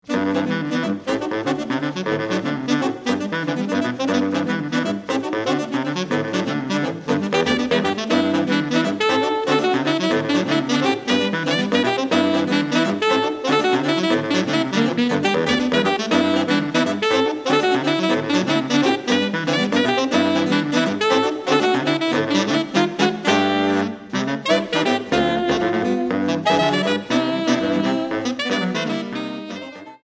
4 Saxophones (2ATB/alt. Stimmen) et Voix Haute